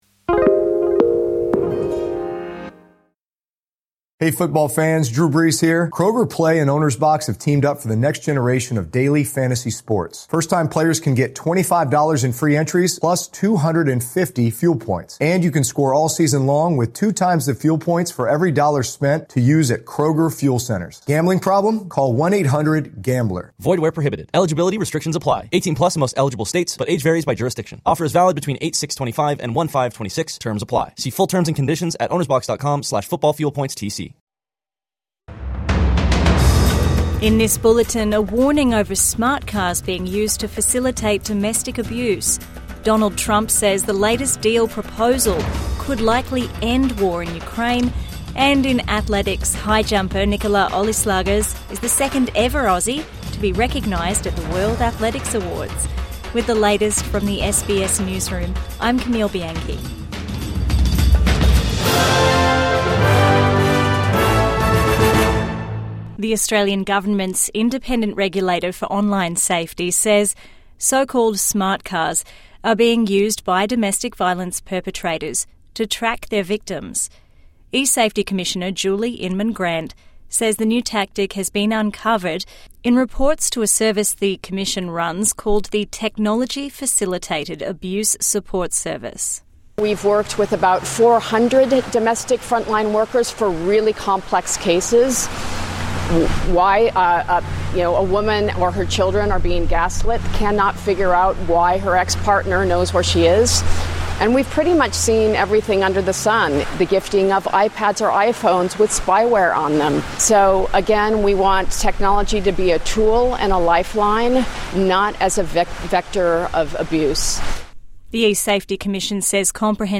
"Weapons on wheels" smart cars abuse warning| Midday News Bulletin 1 December 2025